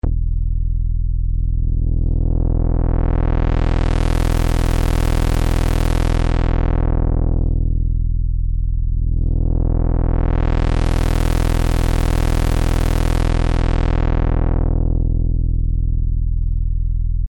Basic_SubBass+zunehmendCutoff.mp3